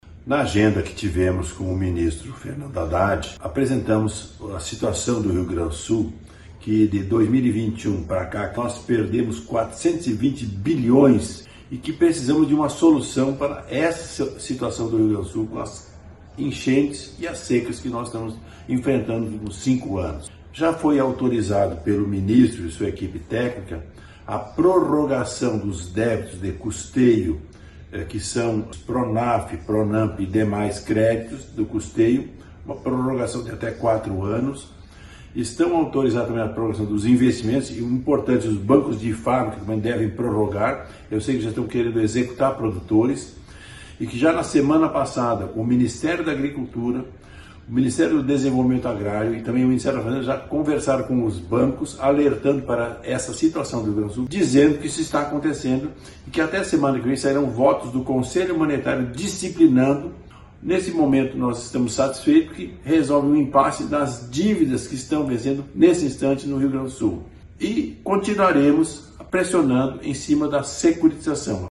Confira manifestação de Luis Carlos Heinze: